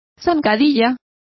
Also find out how zancadilla is pronounced correctly.